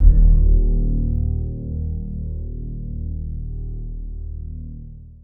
Index of /90_sSampleCDs/Zero G Creative Essentials Series Vol 26 Vintage Keyboards WAV-DViSO/TRACK_17